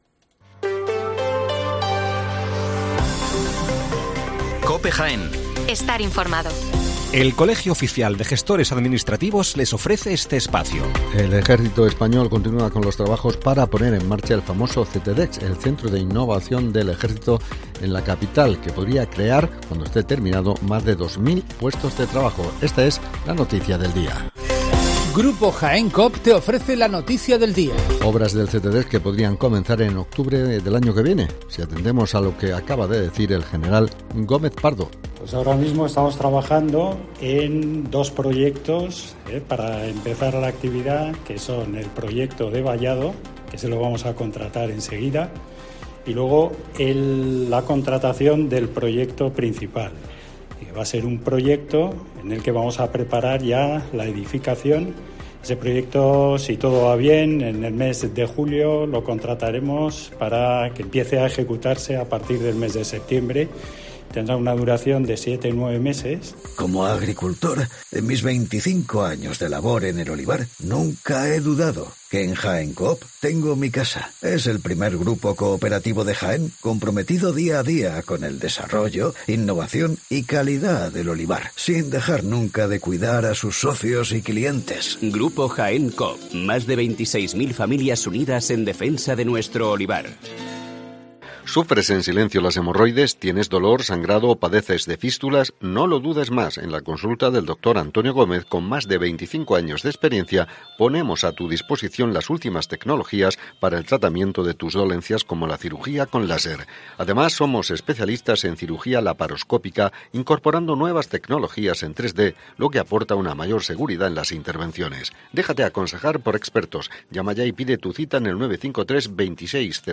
Las noticias locales de las 7'57 horas